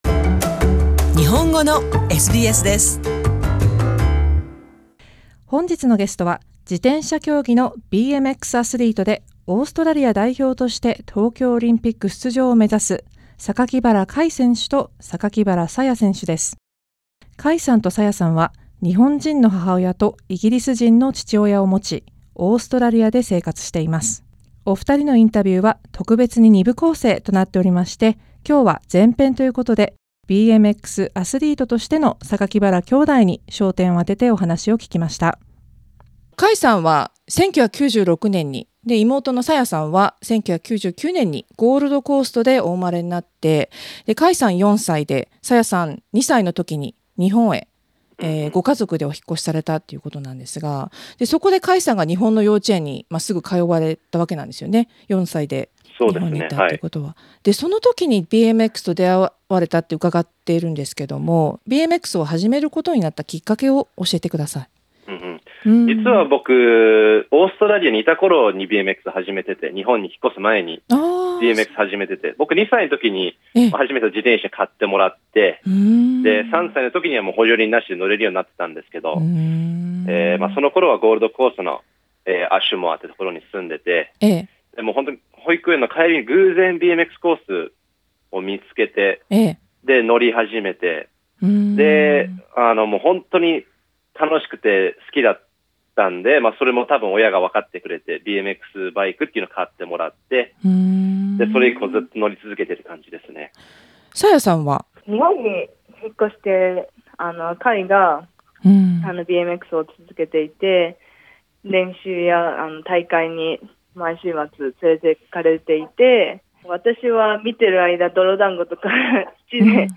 Kai Sakakibara Source: UCI Saya Sakakibara Source: UCI Kai and Saya speak to SBS about what it is like to be BMX atheletes and what their ultimate goals are at the Tokyo 2020. This is Part 1 of Kai and Saya's interview.